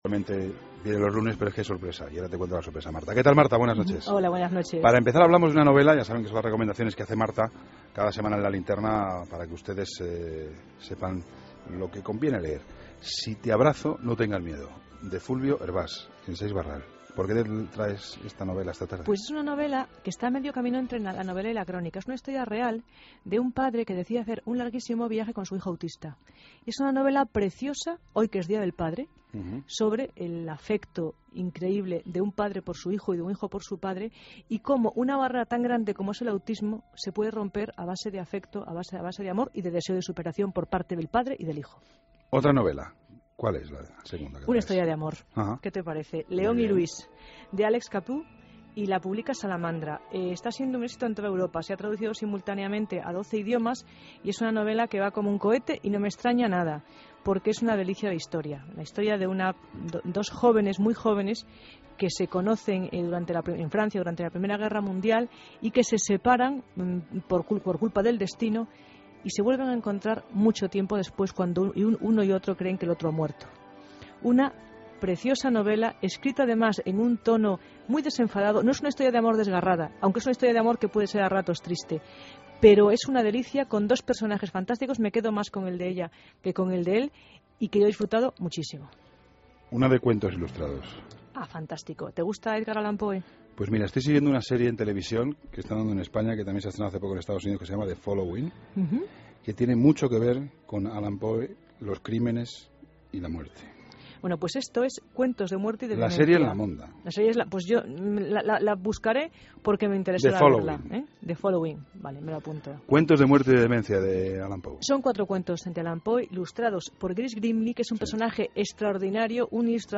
AUDIO: Entrevista a Alicia Giménez Bartlett, autora de 'Nadie quiere saber'